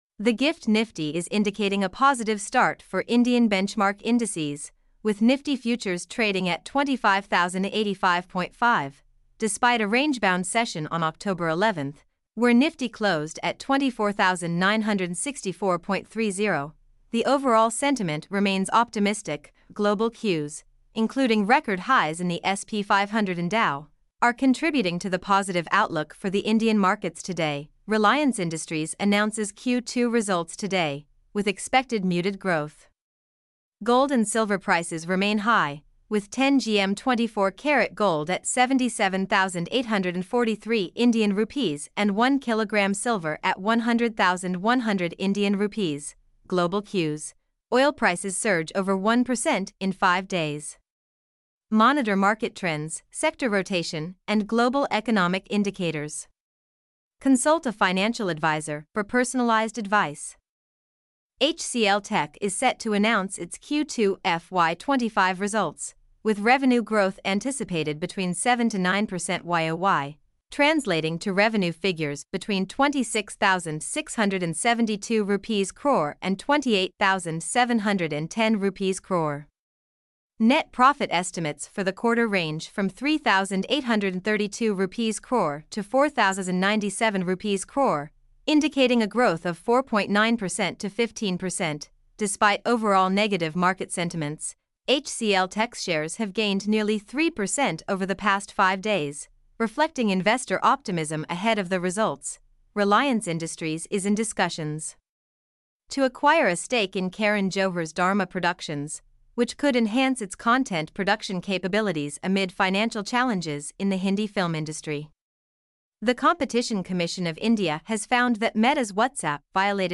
mp3-output-ttsfreedotcom-7.mp3